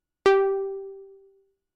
Roland Juno 6 PW Pluck " Roland Juno 6 PW Pluck F4 （PW Pluck67127
描述：通过Modular Sample从模拟合成器采样的单音。
标签： FSharp4 MIDI音符-67 罗兰朱诺-6 合成器 单票据 多重采样
声道立体声